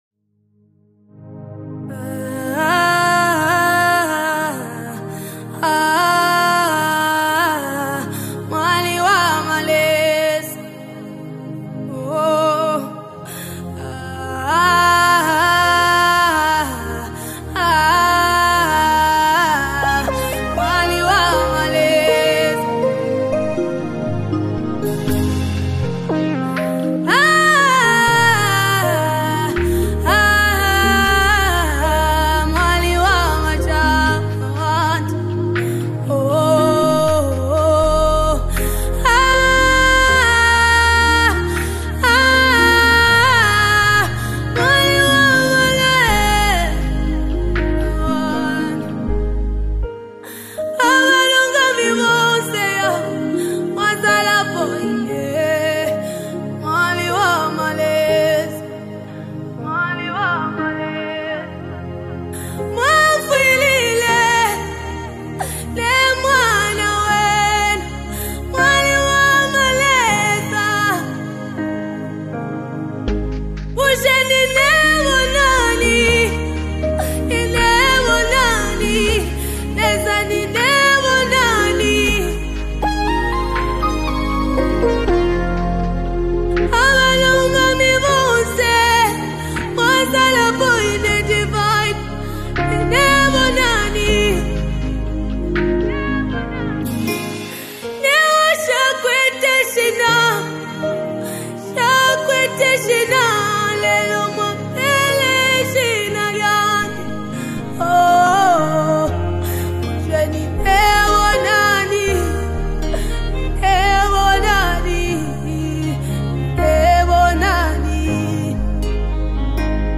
2024 MOST DOWNLOADED ZAMBIAN WORSHIP SONGS
heartfelt worship anthem
passionate delivery